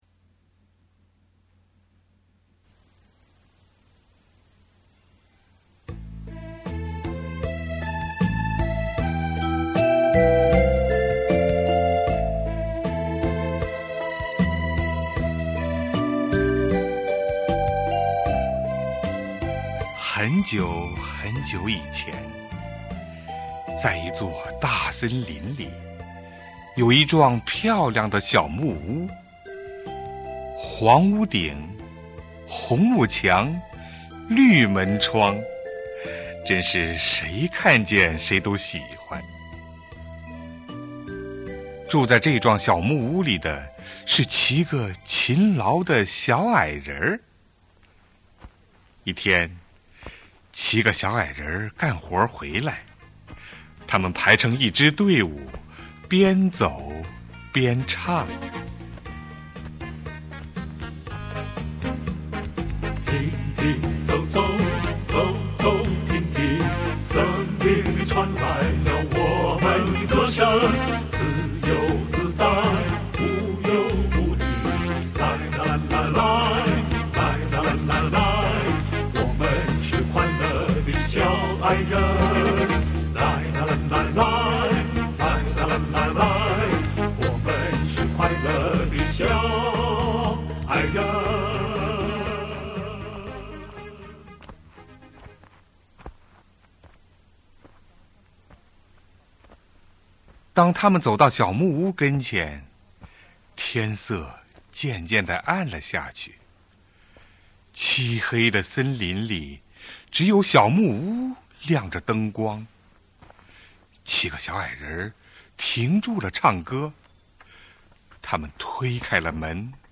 Audiobook «Snow White» in Chinese (白雪公主)